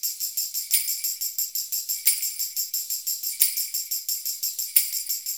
Index of /90_sSampleCDs/USB Soundscan vol.56 - Modern Percussion Loops [AKAI] 1CD/Partition A/04-FREEST089